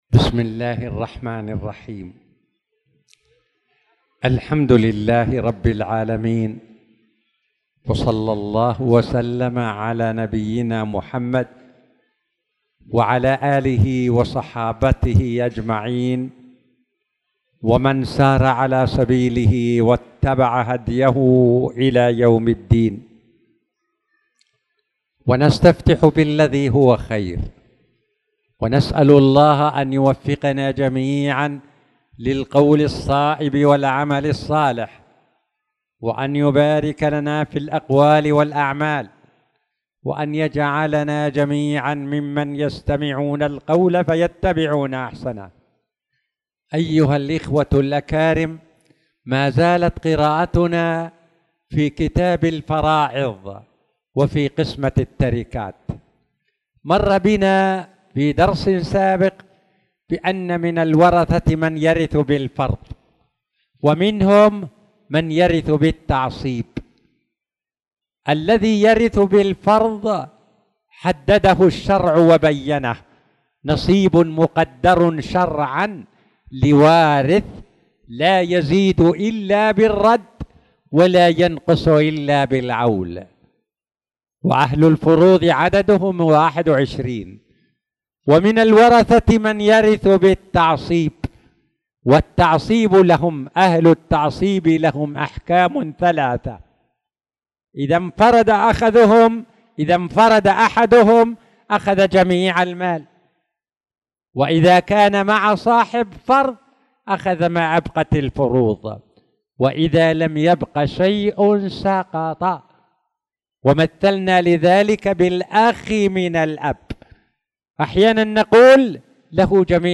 تاريخ النشر ٢٦ شوال ١٤٣٧ هـ المكان: المسجد الحرام الشيخ